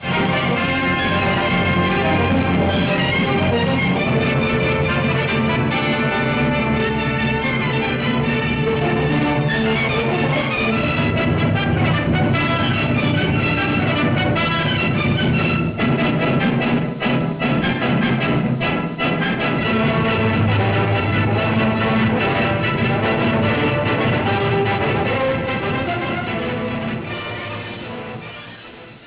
registrato dai nastri del film - effetti sonori